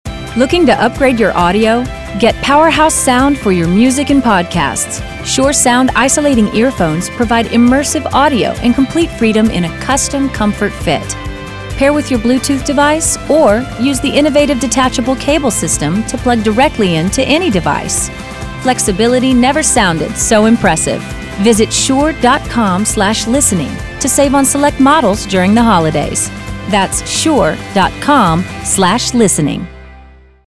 Female – Clean, Positive, Tech